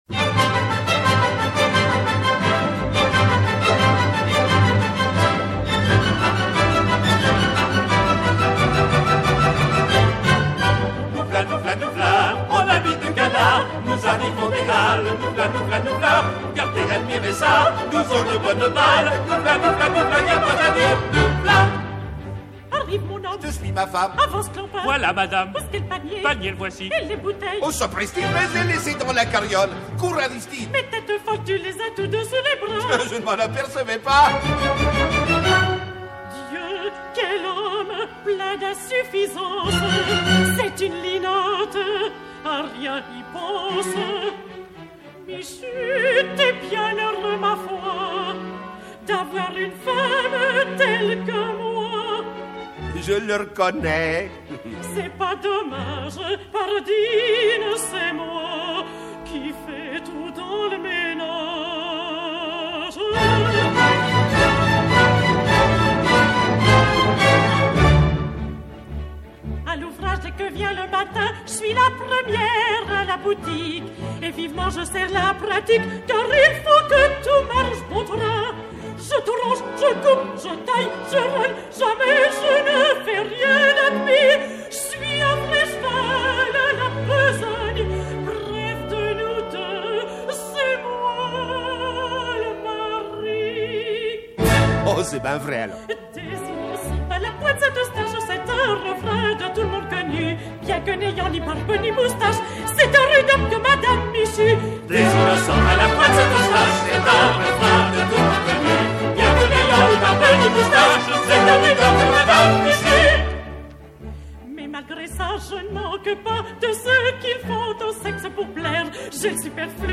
Trio et Couplets